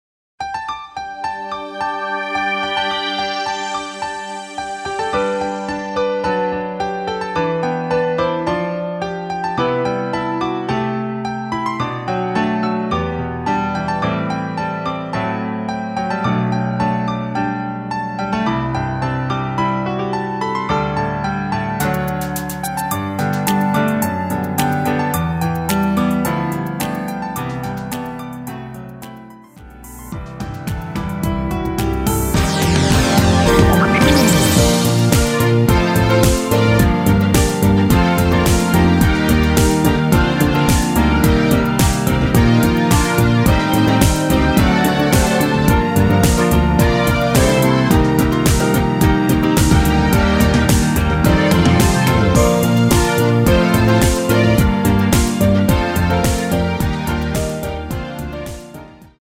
원키가 거의 여자키라 남자분이 부르실수 있는 키로 제작된 MR 입니다.